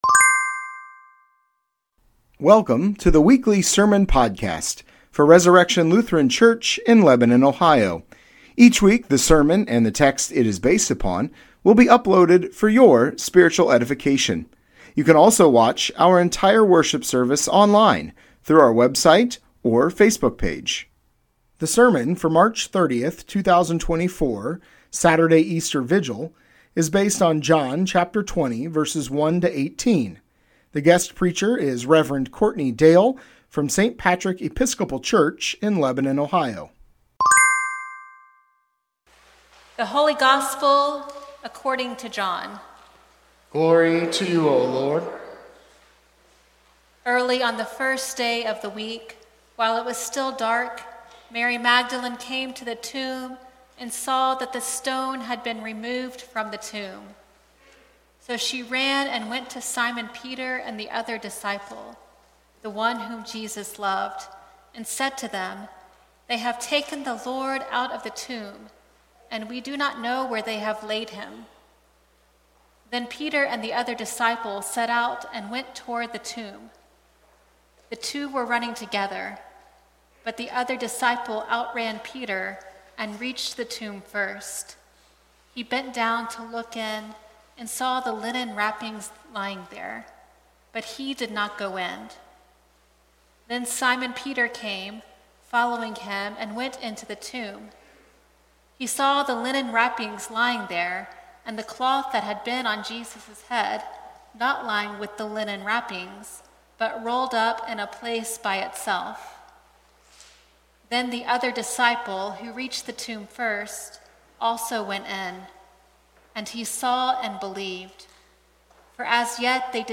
Sermon Podcast Resurrection Lutheran Church